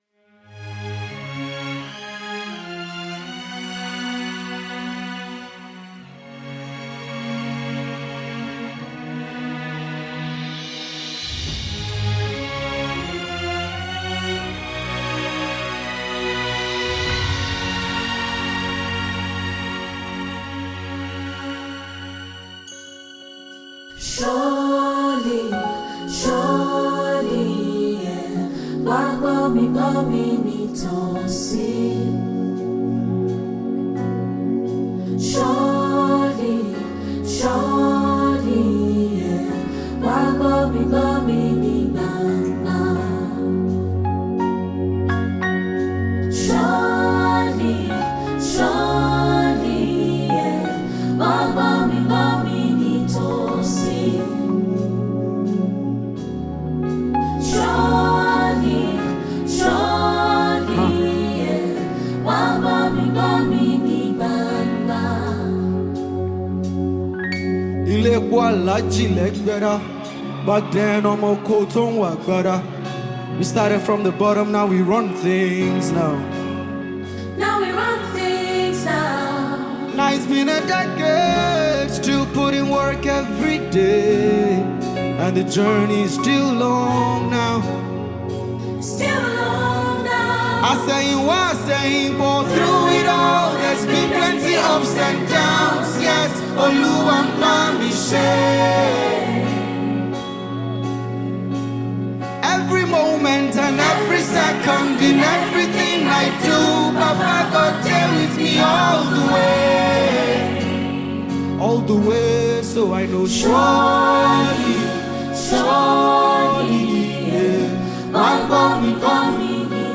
March 31, 2025 Publisher 01 Gospel 0